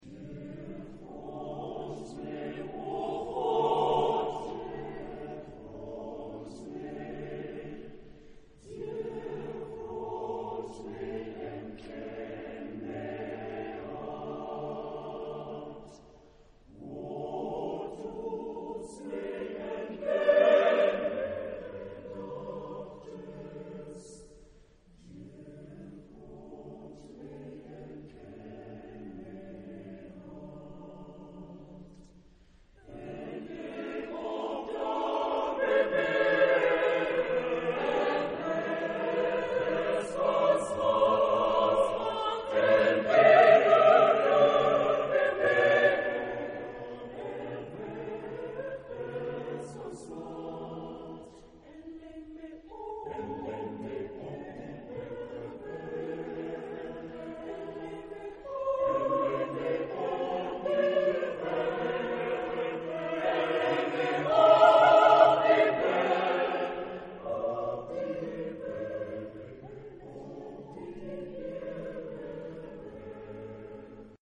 Genre-Style-Forme : Sacré ; Psaume ; Prière
Caractère de la pièce : pieux ; calme
Tonalité : ré majeur